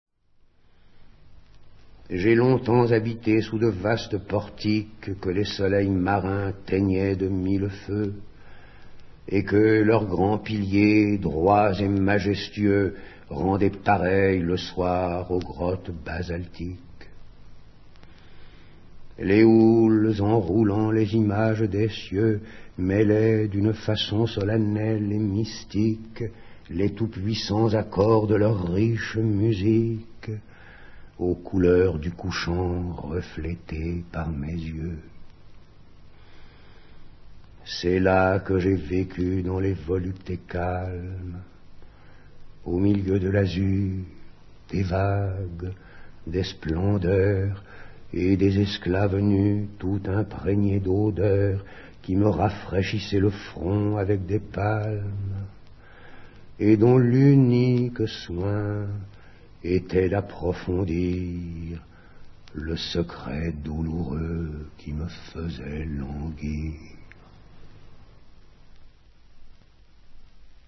dit par Pierre BLANCHAR